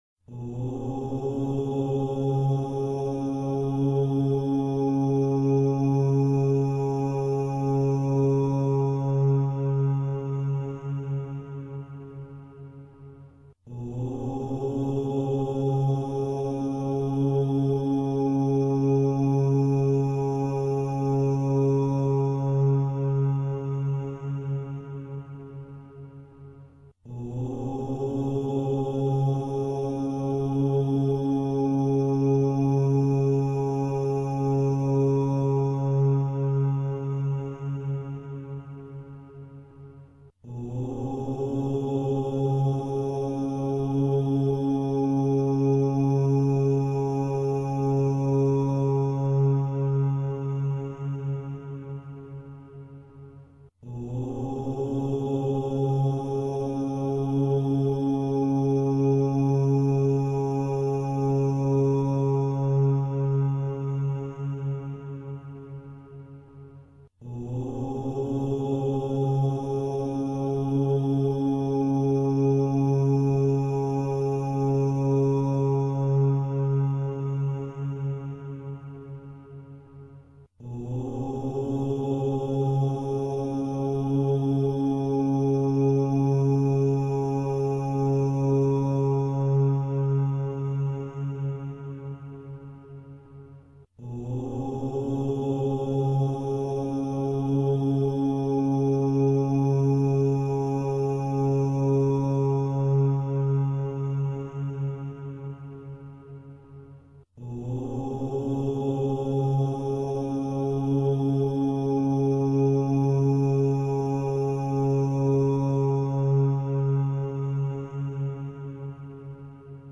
OM Meditation.mp3